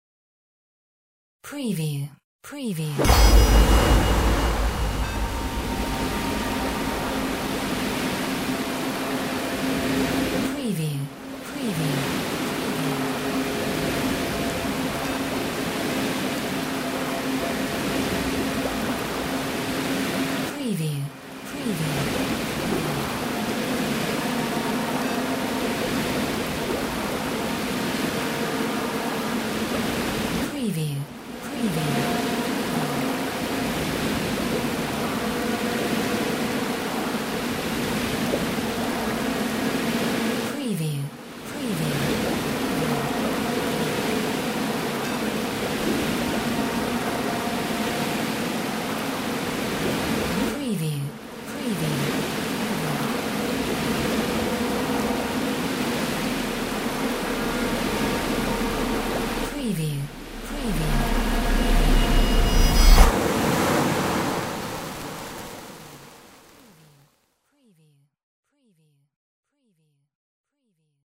Stereo sound effect - Wav.16 bit/44.1 KHz and Mp3 128 Kbps
previewSCIFI_MAGIC_WATER_FULL_WBHD05B.mp3